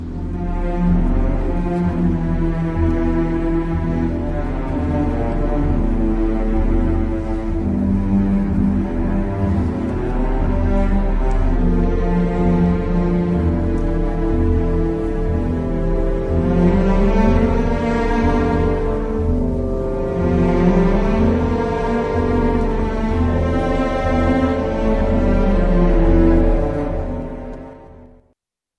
اپرای